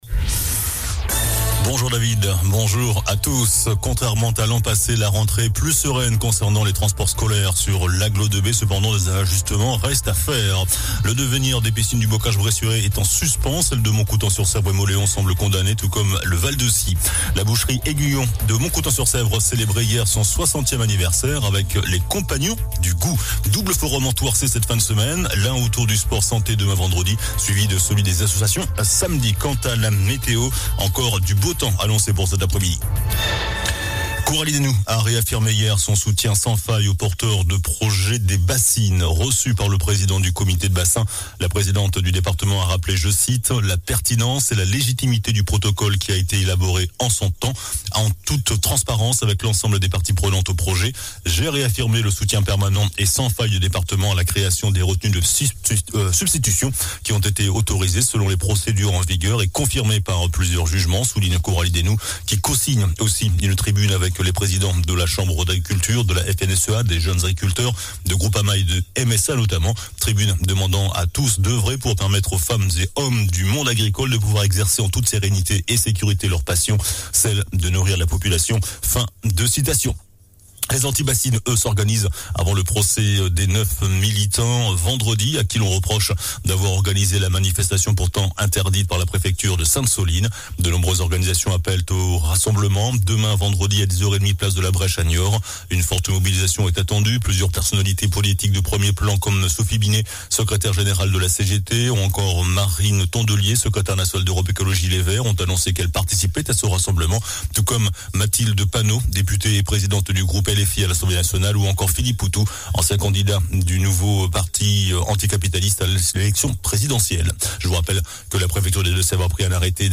JOURNAL DU JEUDI 07 SEPTEMBRE ( MIDI )